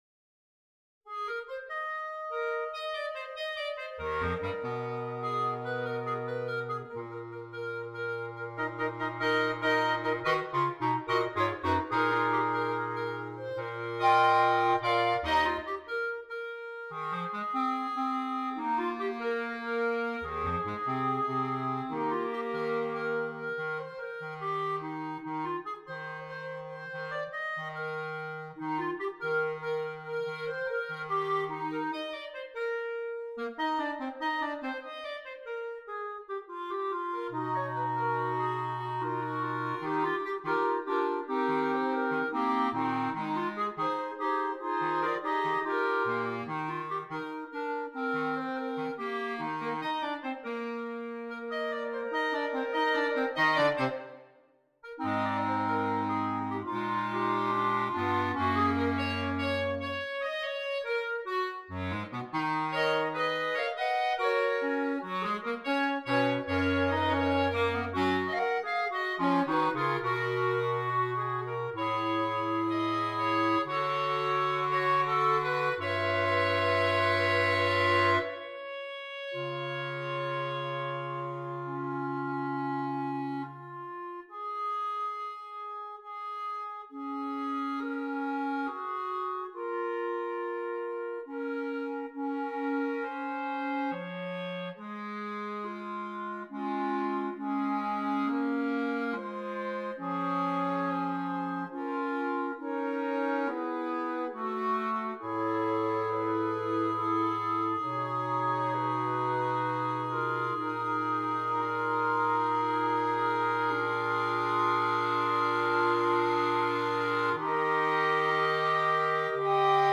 Gattung: Für 4 Klarinetten und Bassklarinette
energiegeladene Ouvertüre